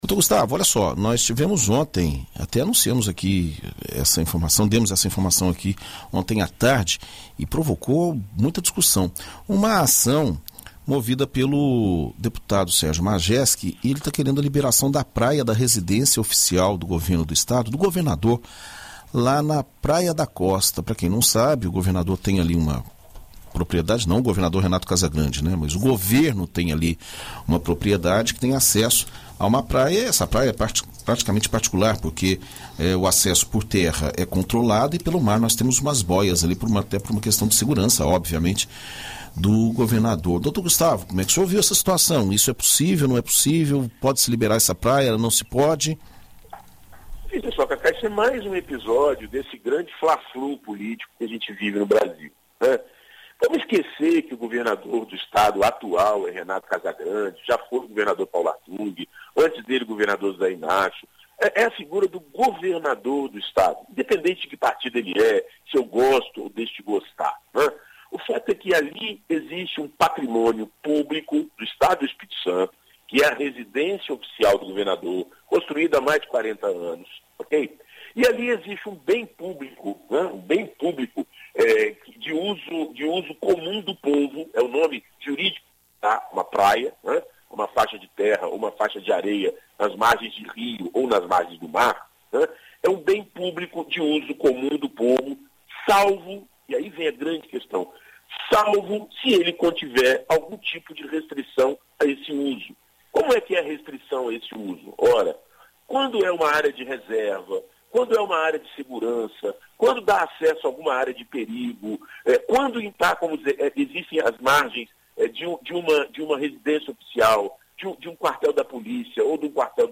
Na coluna Direito para Todos desta sexta-feira (23), na BandNews FM Espírito Santo